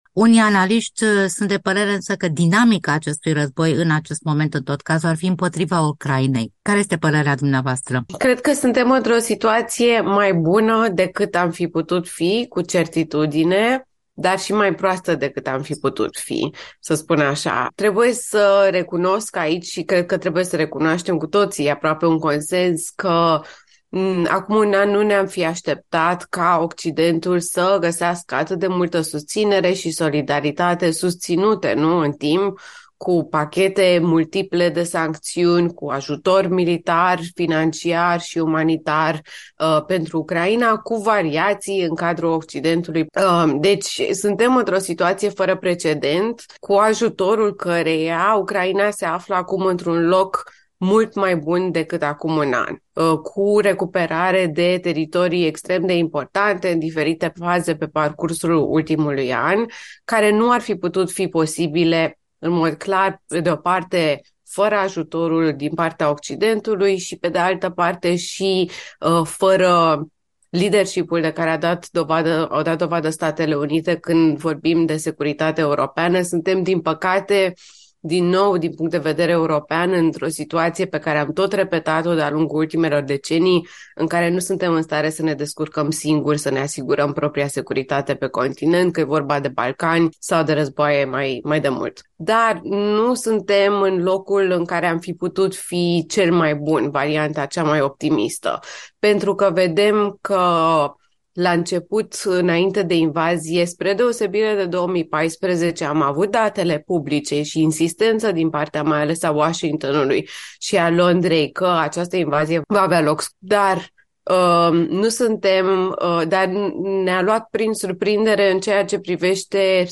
Panorama Interviu